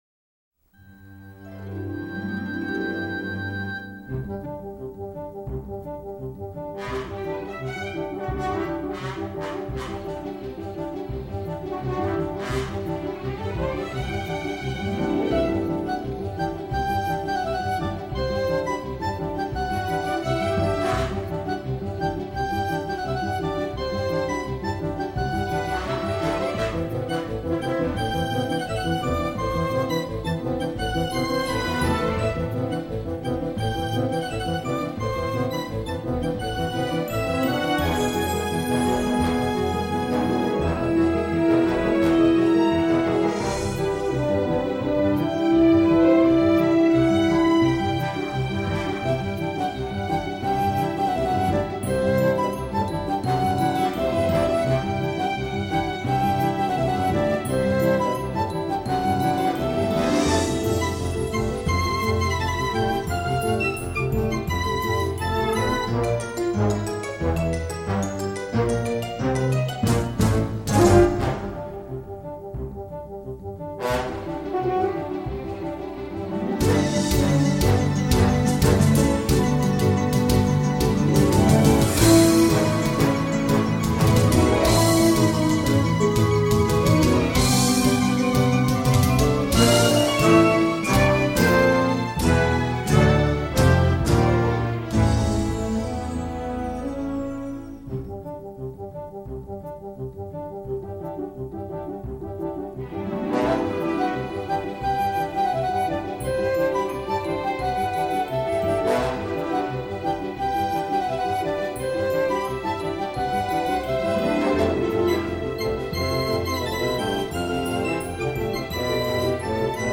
Bref, c’est rythmé, sympathique mais un peu fauché.